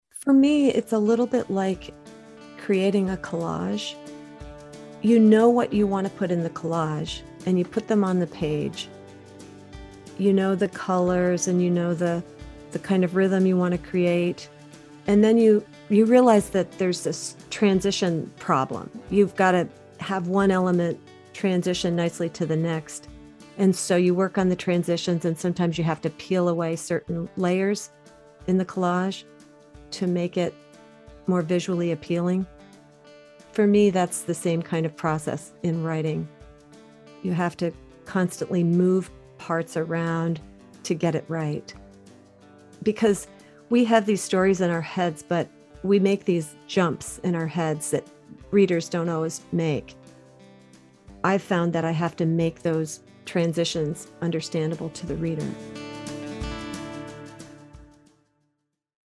Interviews and audio